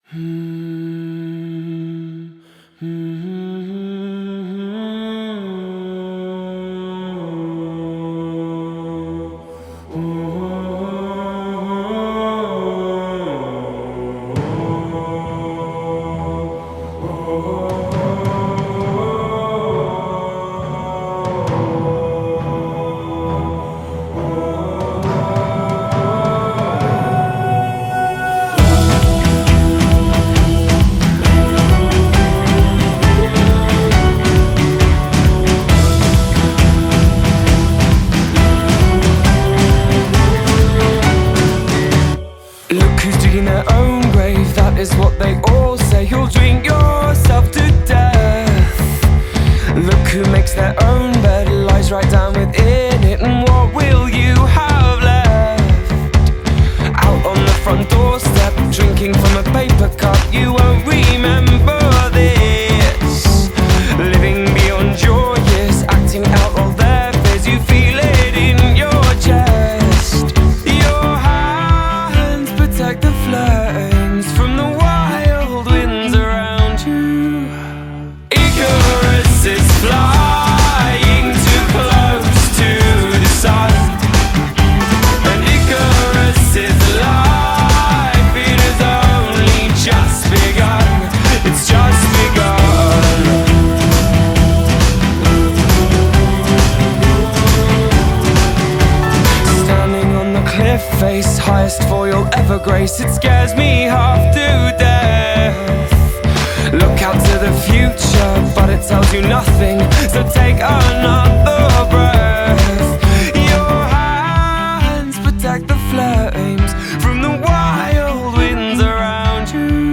modern day pop song